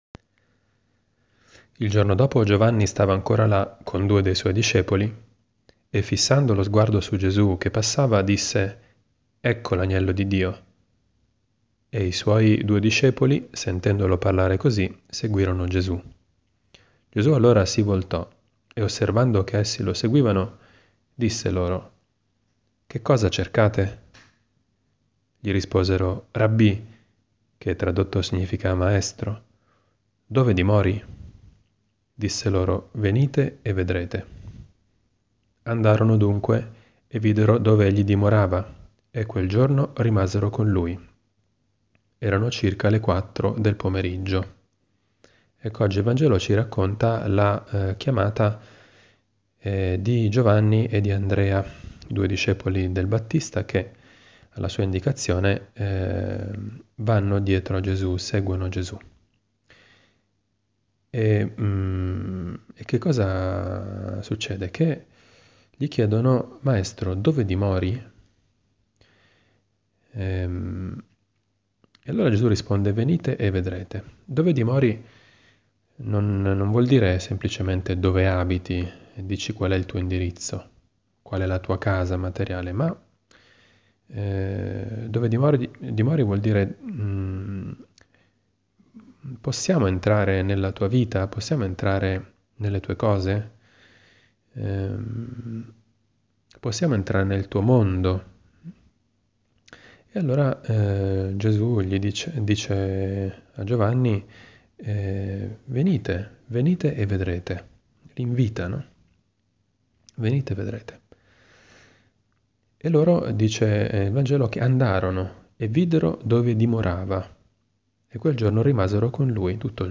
Commento al vangelo (Gv 1, 35-42) di domenica 14 gennaio 2018, II domenica del Tempo Ordinario.